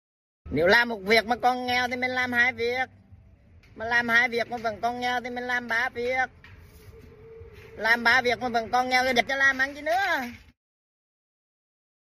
Thể loại: Câu nói Viral Việt Nam
Description: Âm thanh meme hài hước Viral TikTok Nếu làm 1 việc còn nghèo Thì mình làm 2 việc, nếu làm 2 việc mà vẫn còn nghèo thì dẹp chứ làm ăn chi nữa (Bản gốc) mp3...